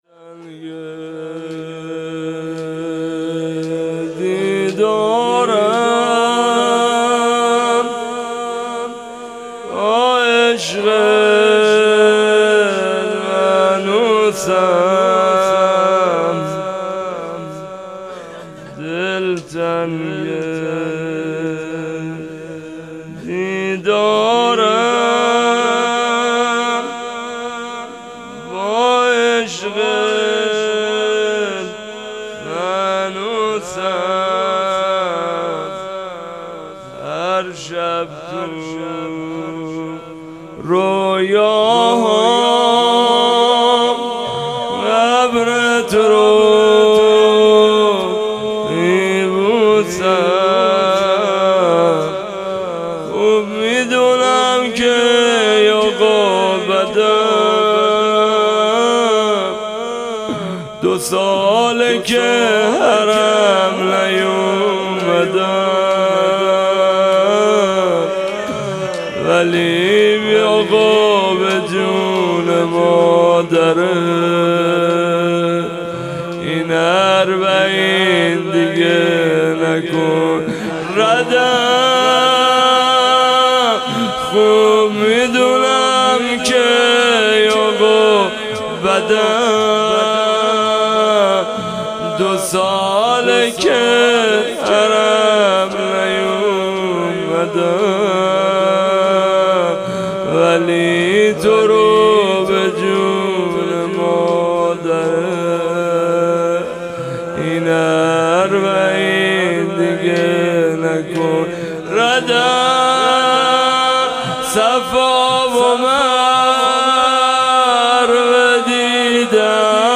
شب دوم - زمینه - دلتنگ دیدارم
هیئت مکتب الزهرا (س)